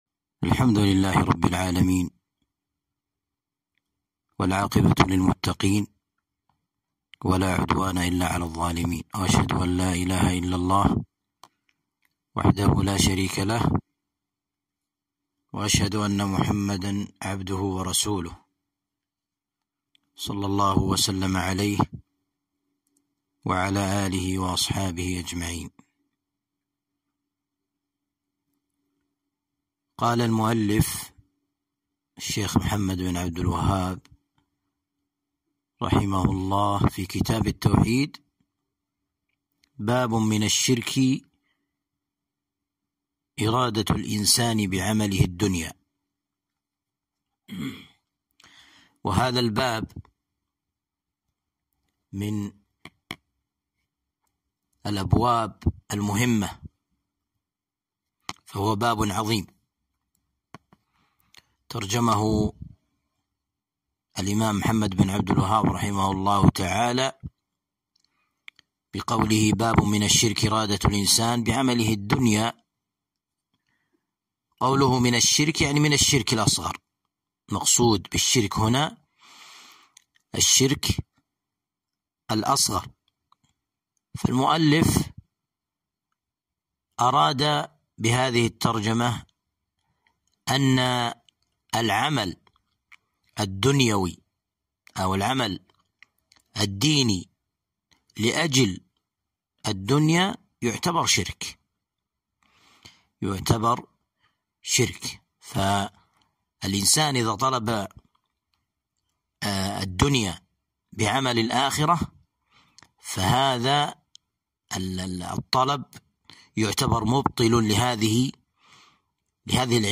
درس شرح كتاب التوحيد (37)